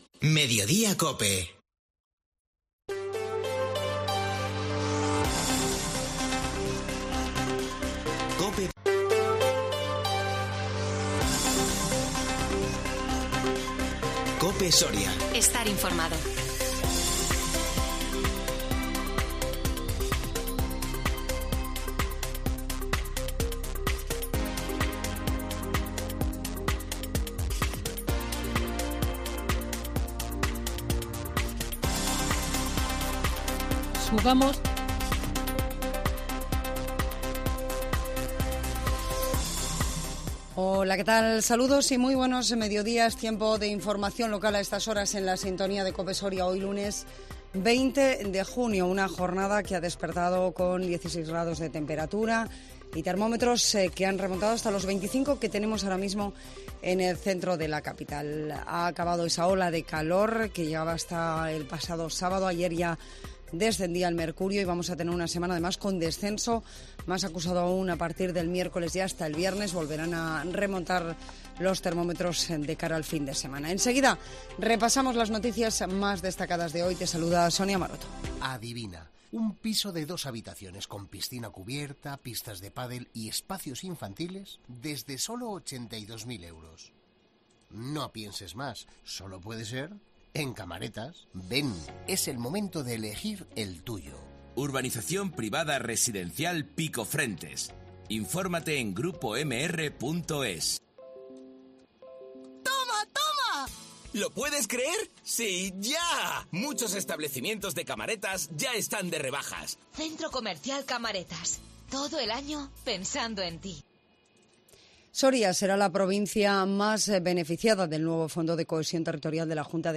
INFORMATIVO MEDIODÍA COPE SORIA 20 JUNIO 2022